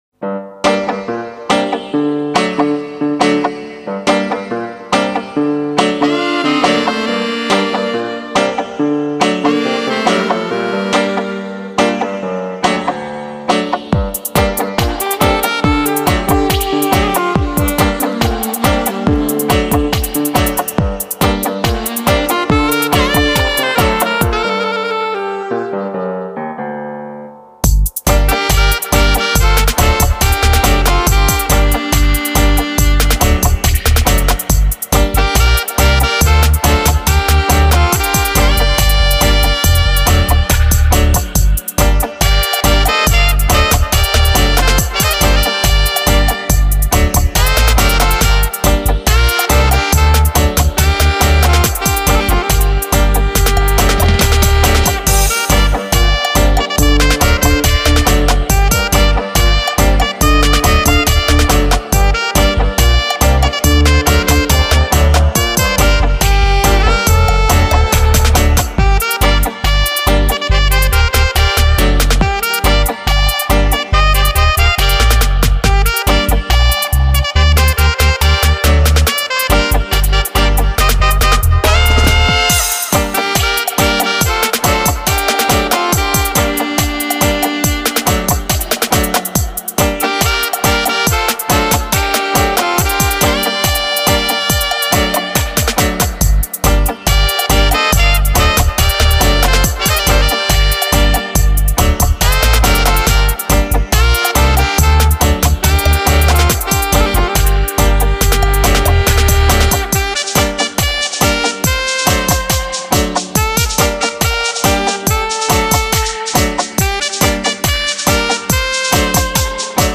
sax, guitar
vocals